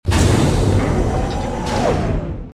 dooropen.ogg